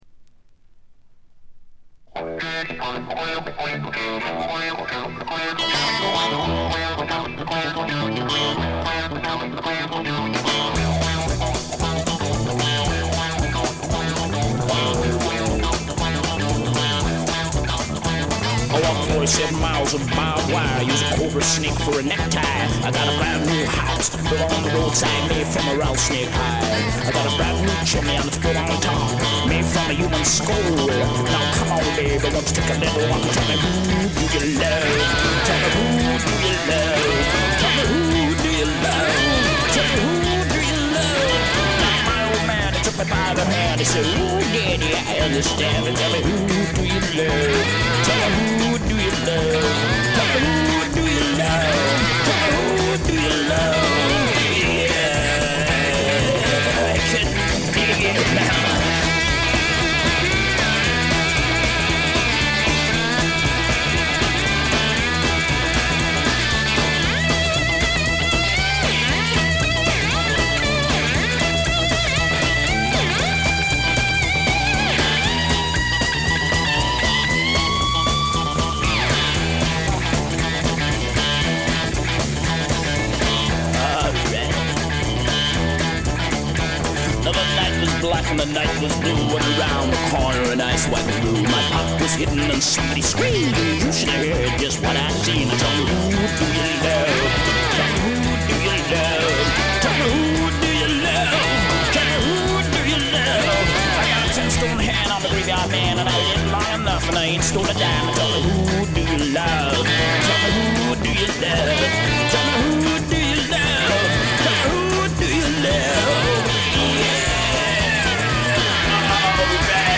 へヴィー・ブルース、ハード・スワンプな名作を残しました。
試聴曲は現品からの取り込み音源です。
lead vocals
steel guitar, backing vocals, mandolin, marimba
drums, percussion
Recorded at I.B.C. Studios, London, September 1969